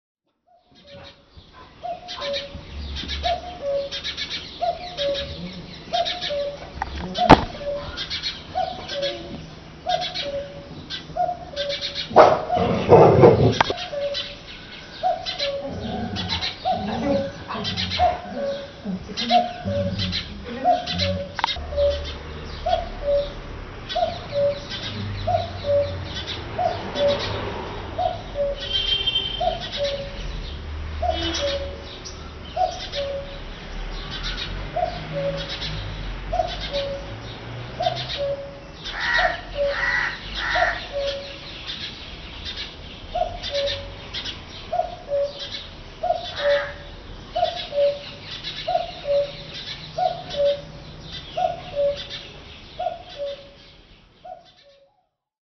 Common Cuckoo is well known for its typical call which announces the spring!
CUCULUS CANORUS
The male song is very typical “cuck-oo”, with higher first note. This song is far-carrying.
We can hear some variants such as “kuk-kuk-kuk-oo”, and occasionally a single “kuk”. When excited, it also utters a short “gowk gowk gowk”.
Female gives a rapid bubbling sound “kwik-kwik-kwik”.
CUCULUS-CANORUS.mp3